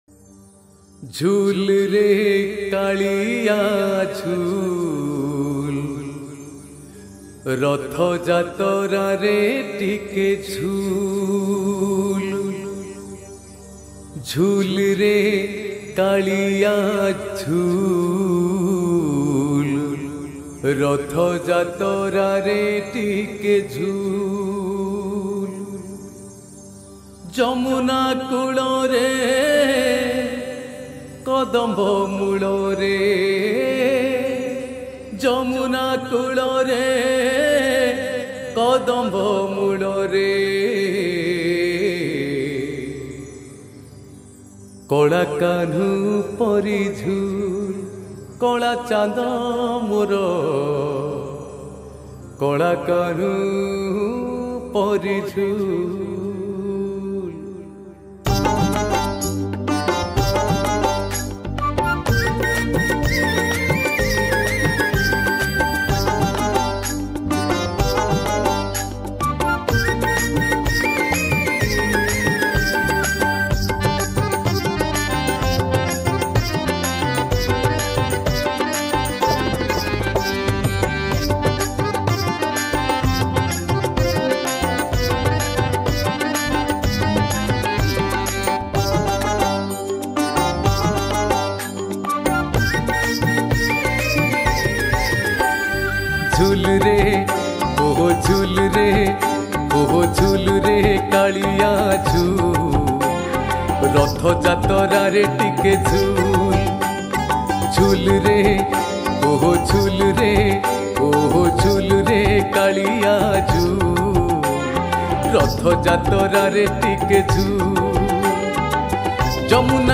Ratha Yatra Odia Bhajan 2022 Songs Download